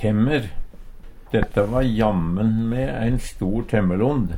temmer - Numedalsmål (en-US)